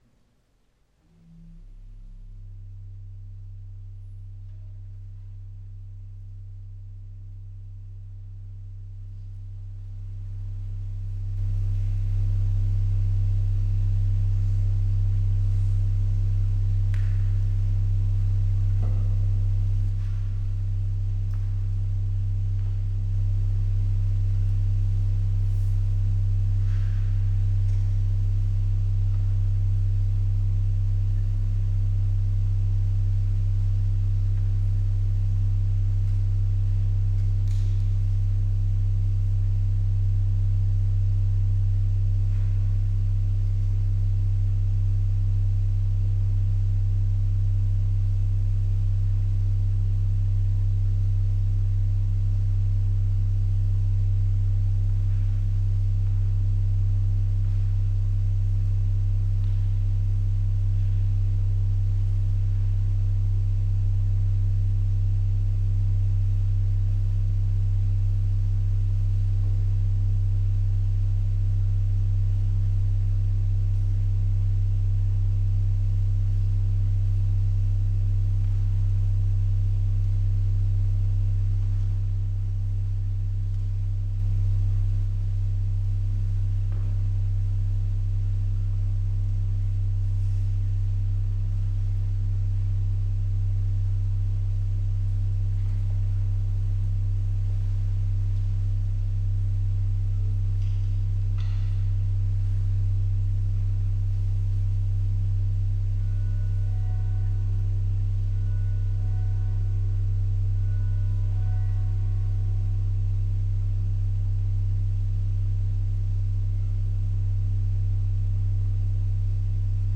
Solo organ performance